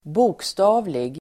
Ladda ner uttalet
bokstavlig adjektiv, literal Uttal: [²b'ok:sta:vlig]